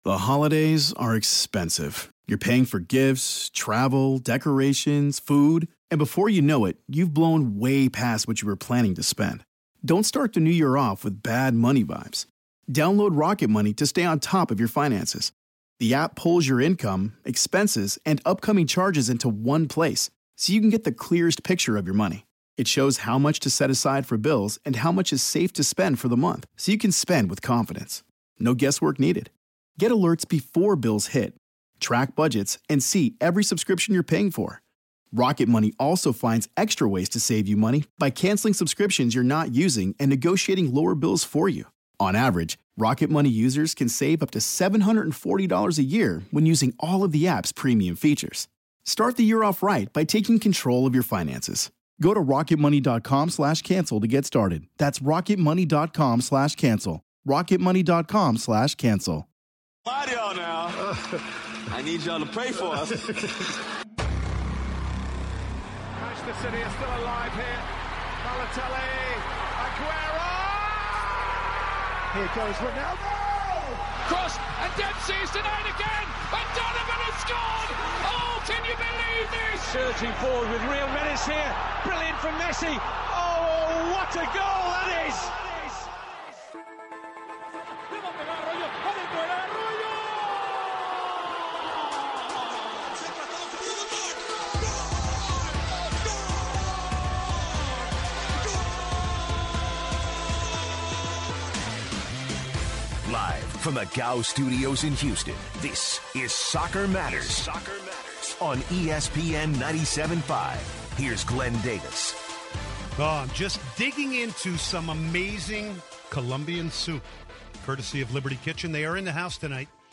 Callers bring up the discussion of refs, penalties, and red cards from the game and if they are preserving the integrity of the players as well as the game during the finals.